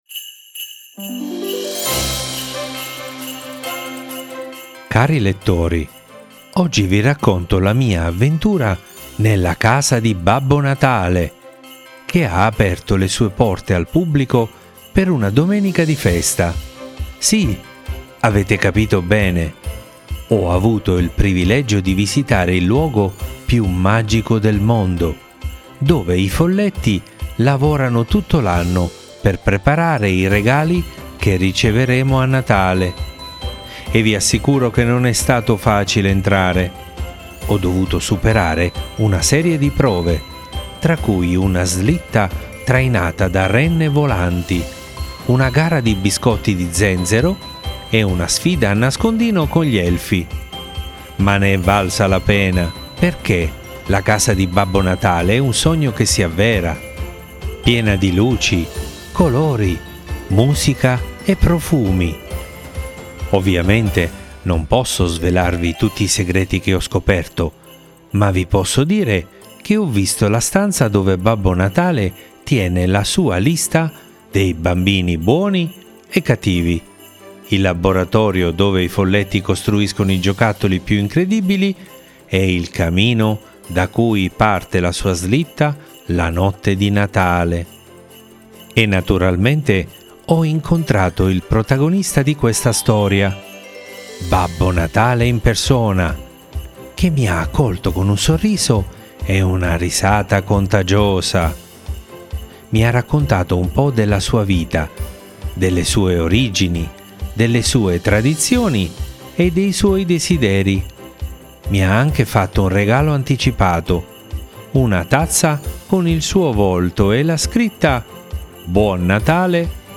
Leggi e ascolta la fiaba su “la casa di Babbo Natale”